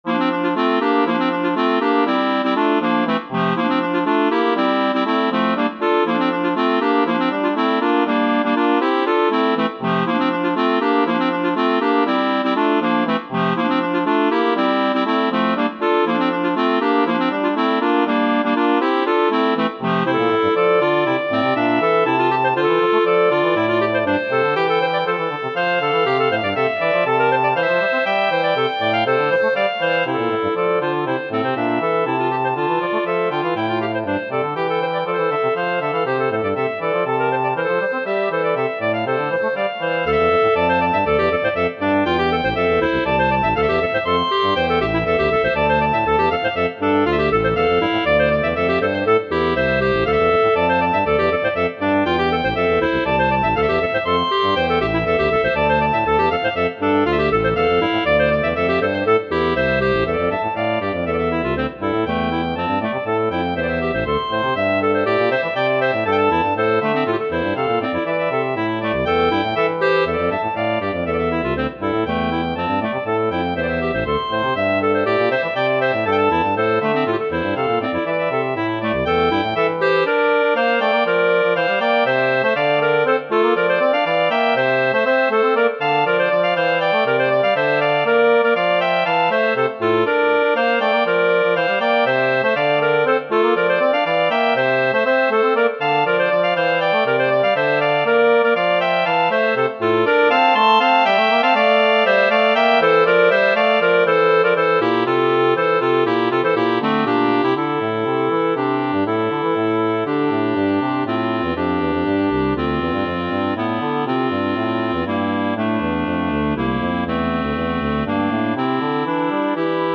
Clarinet trio in Em
This is a blue-mooded clarinet trio that i have composed few years ago.
There is a lot of texture in it for every player and it could be difficult to play, to get everything to its place.